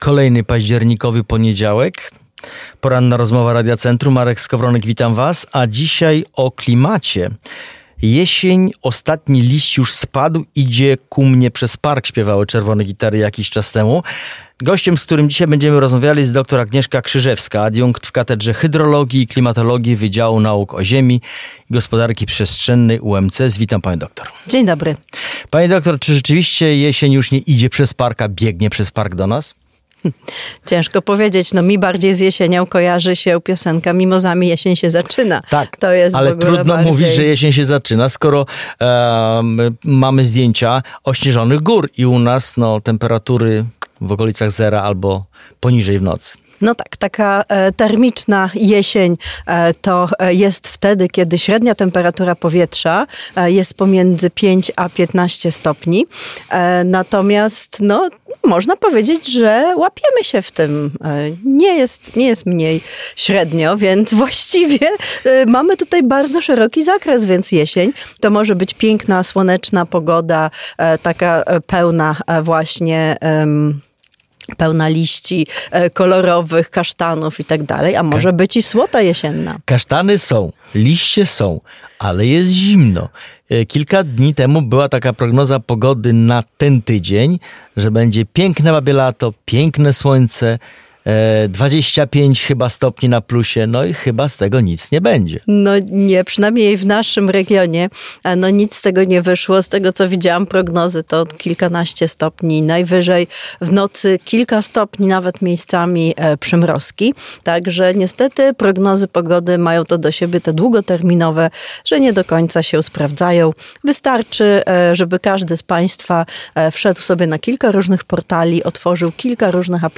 Cała rozmowa o klimacie i jesiennej aurze dostępna poniżej oraz na naszym kanale na Spotify .